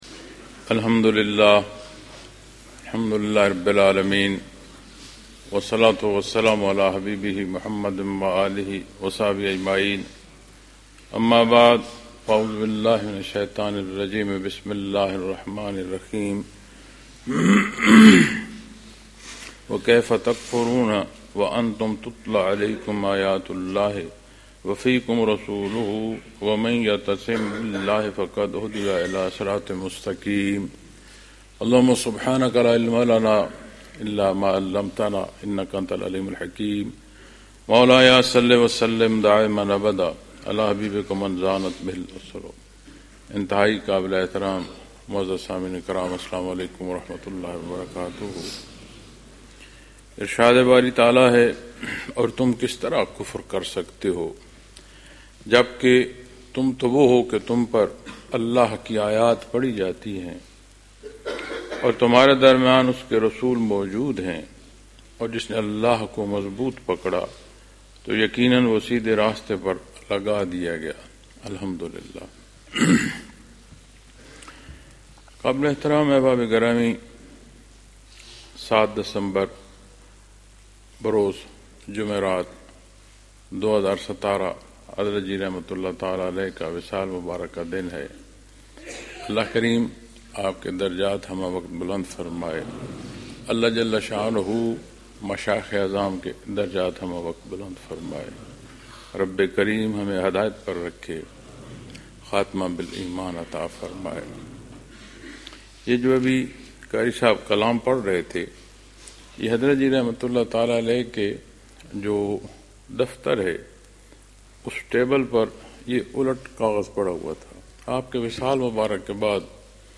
Lectures in Munara, Chakwal, Pakistan